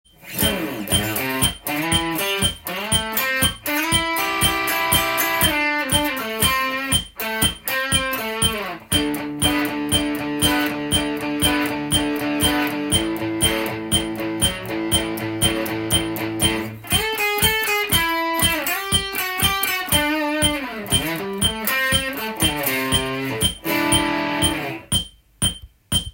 ブルースで４バース
４小節ソロを弾き、次の４小節で伴奏をします。
そして、その次の４小節でソロを弾きます。